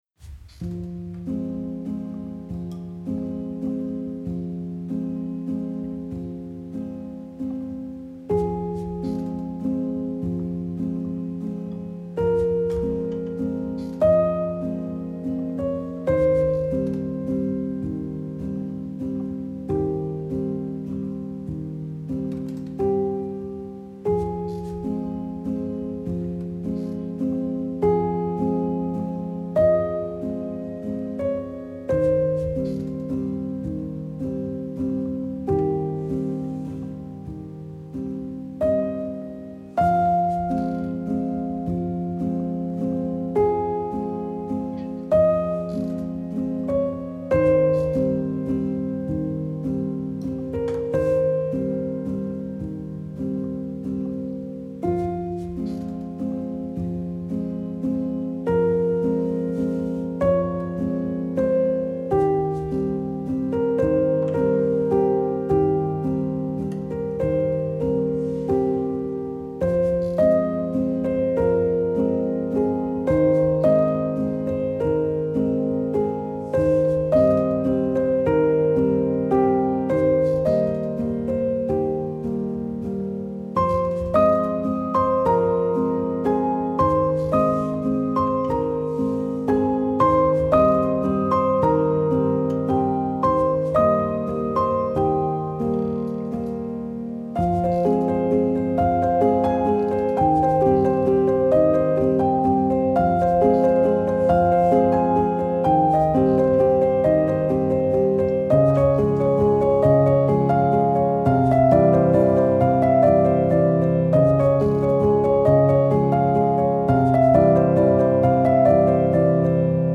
آهنگساز و نوازنده پیانو